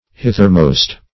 Hithermost \Hith"er*most`\, a. Nearest on this side.